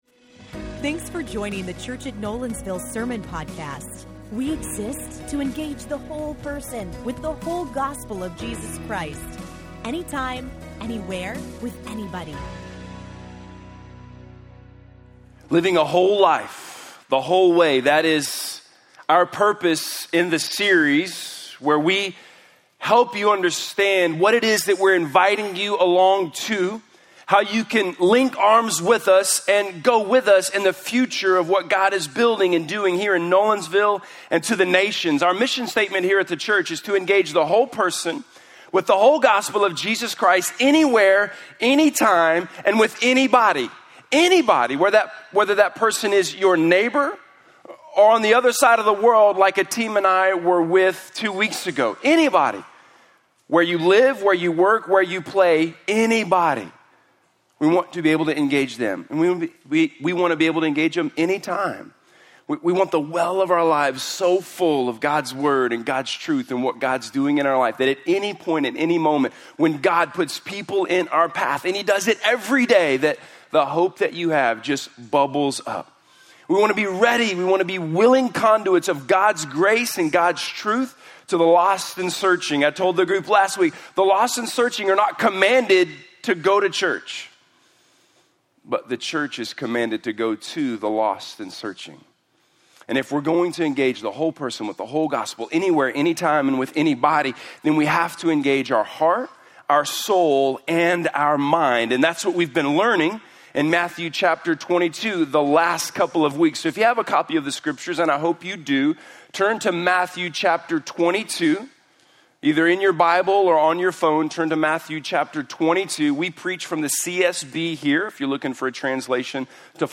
Loving God with All of My Soul - Sermon - Nolensville
Scripture Matthew 22:36-40 Sermon Audio